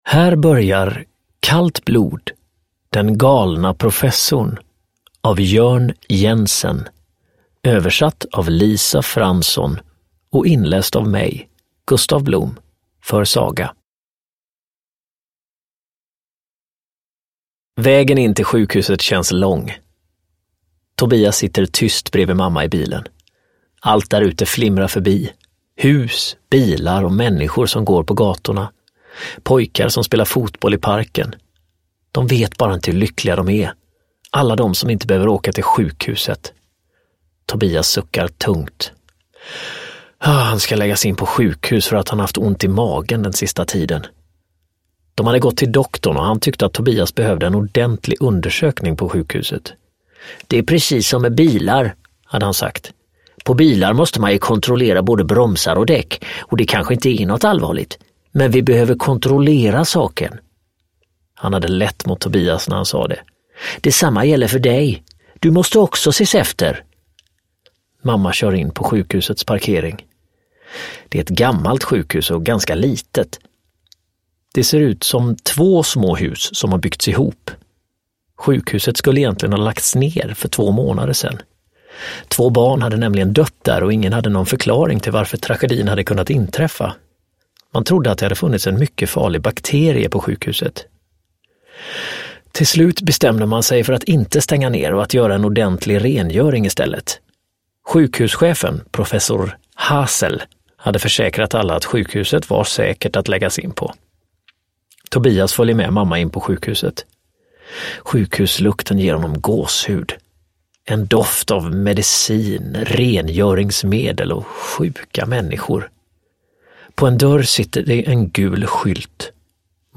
Kallt blod - Den galna professorn (ljudbok) av Jørn Jensen